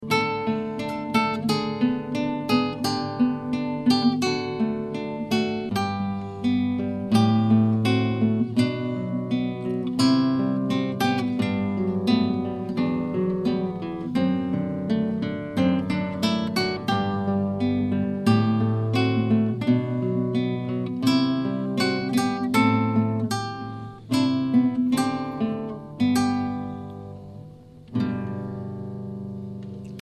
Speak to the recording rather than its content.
Ashington Folk Club - Spotlight 20 October 2005